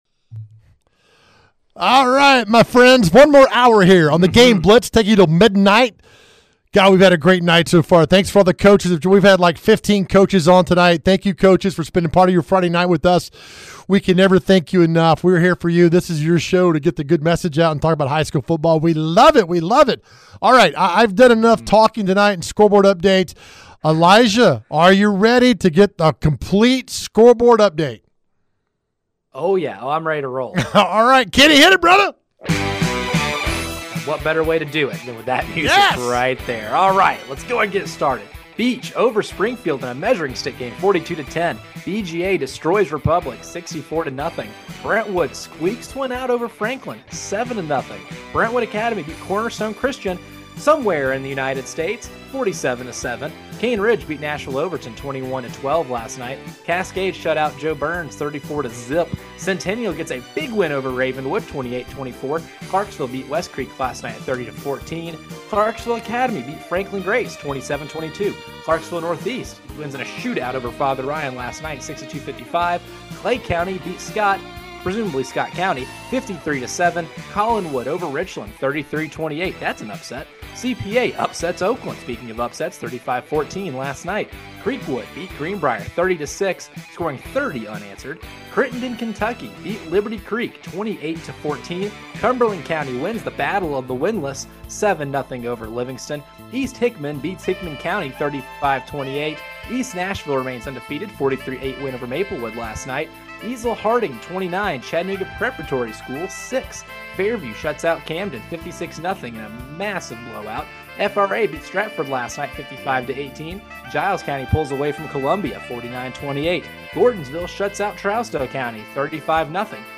They bring you six hours of everything in the world of Middle TN High School Football. We have interviews with coaches and reporters live at the hottest games!